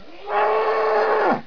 دانلود صدای حیوانات جنگلی 114 از ساعد نیوز با لینک مستقیم و کیفیت بالا
جلوه های صوتی
برچسب: دانلود آهنگ های افکت صوتی انسان و موجودات زنده دانلود آلبوم صدای حیوانات جنگلی از افکت صوتی انسان و موجودات زنده